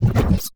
Robotic Back Button 6.wav